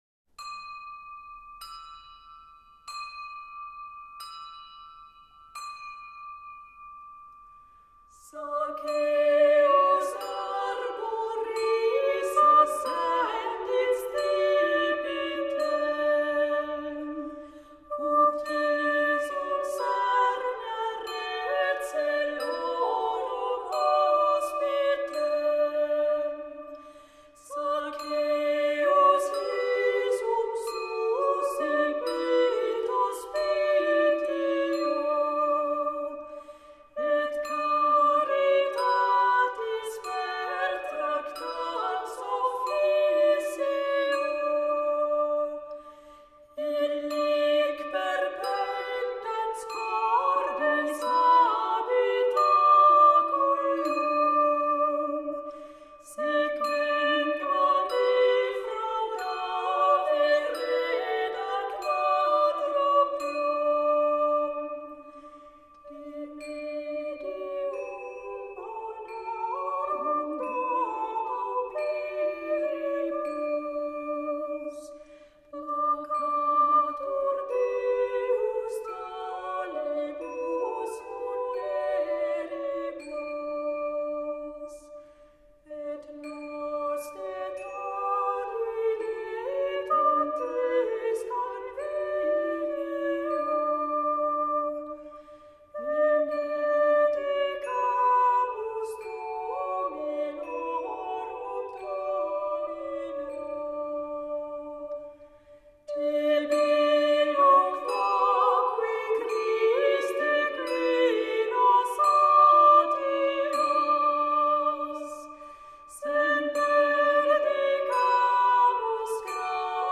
Canon